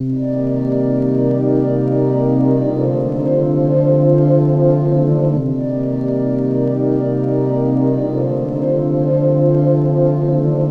Dream Chords.wav